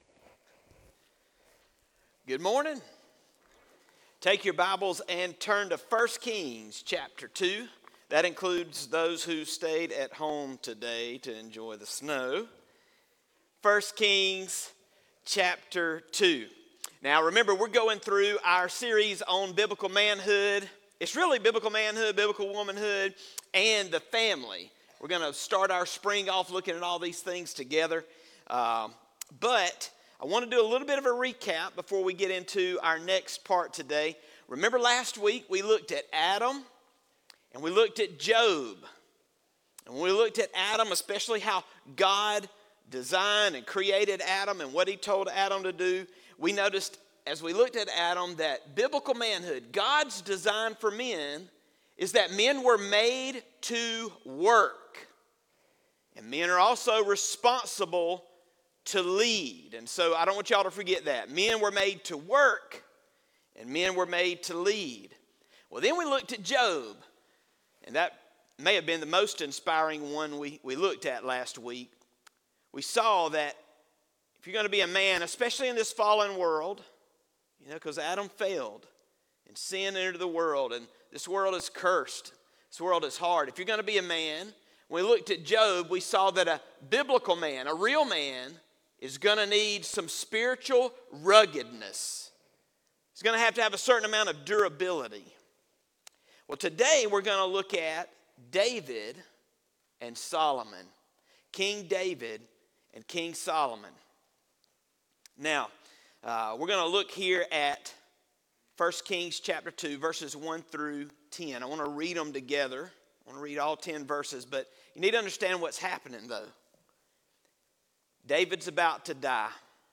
Sermon Audio 1-18.m4a